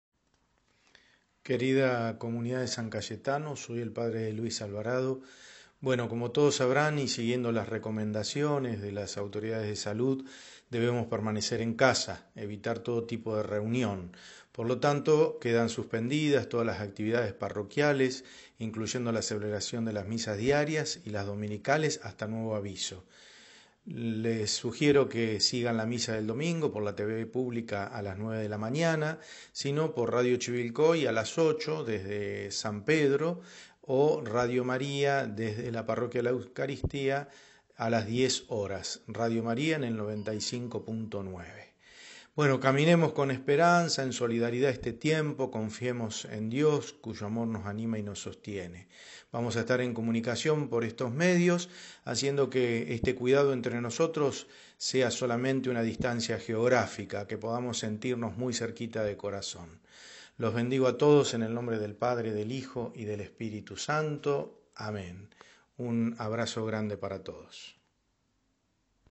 SALUDO Y BENDICIÓN